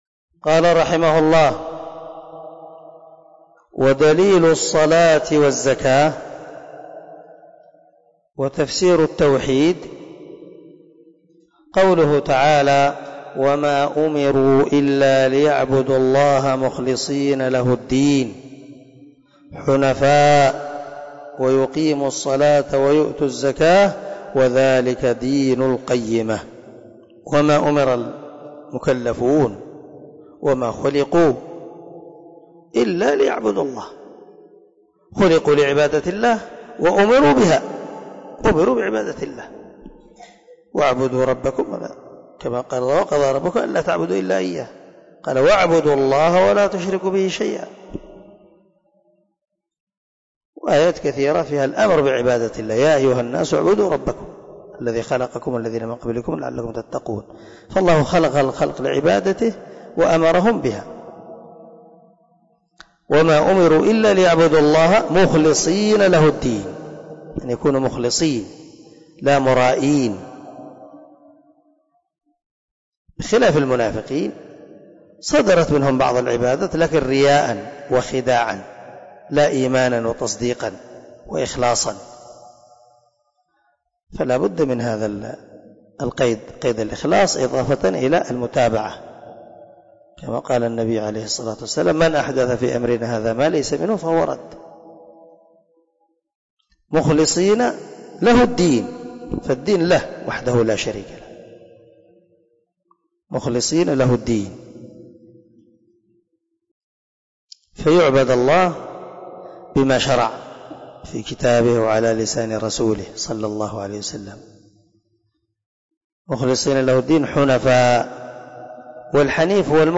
🔊 الدرس 25 من شرح الأصول الثلاثة